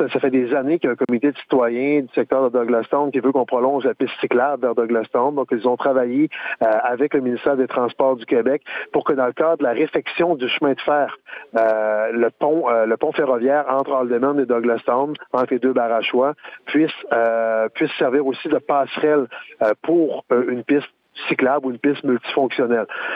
Le maire de Gaspé souligne le travail des citoyens pour en venir à une entente avec le ministère des Transports :